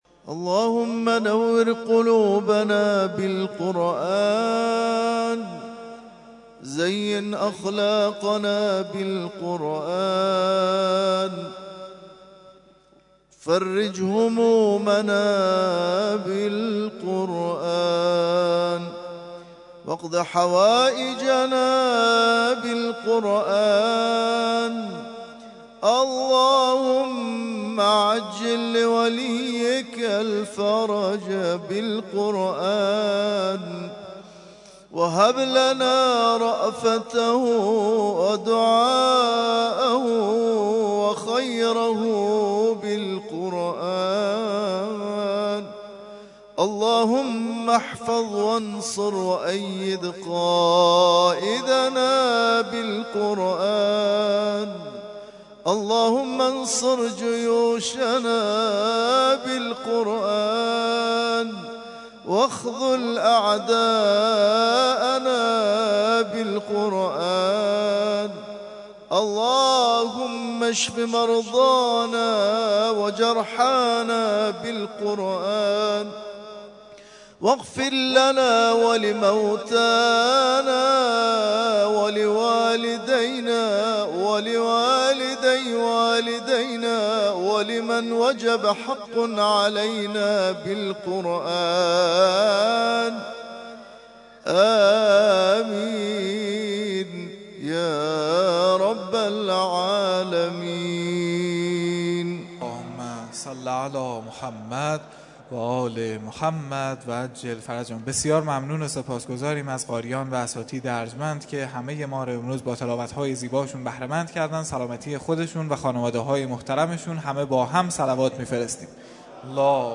ترتیل خوانی جزء۴ قرآن کریم در سال ۱۳۹۷